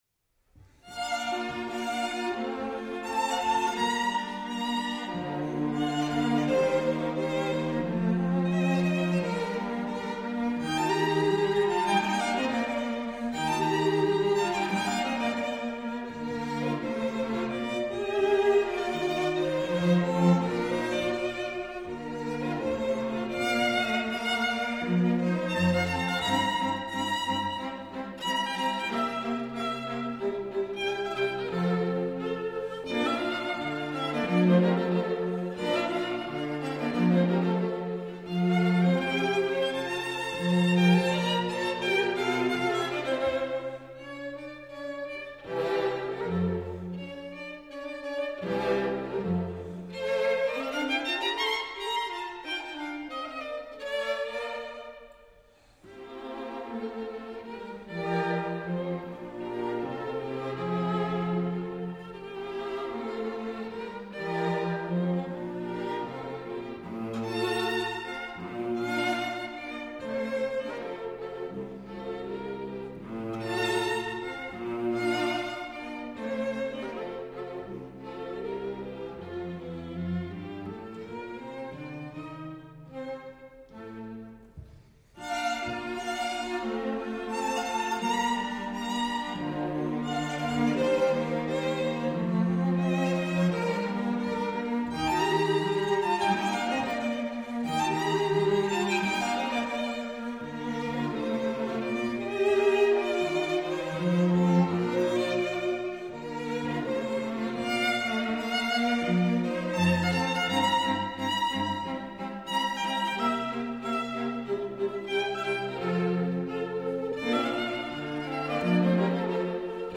String Quartet in B flat major
Andante